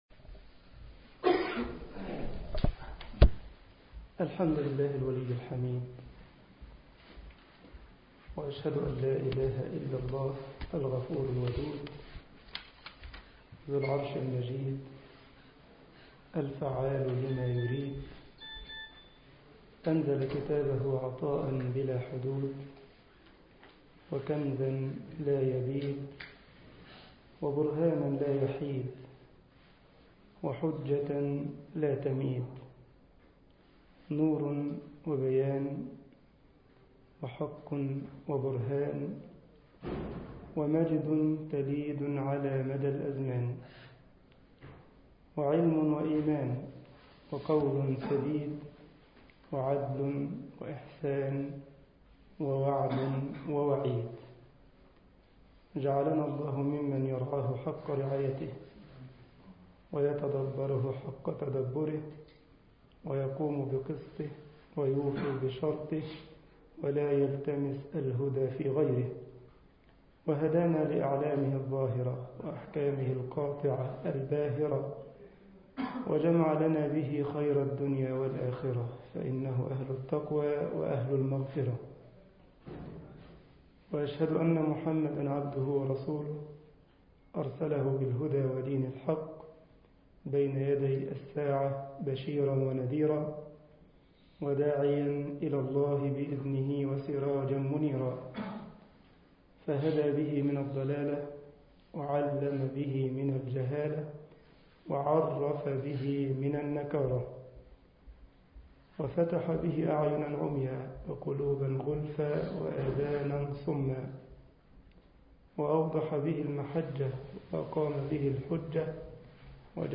مسجد كايزرسلاوترن ـ ألمانيا محاضرة